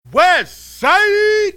Tags: hip hop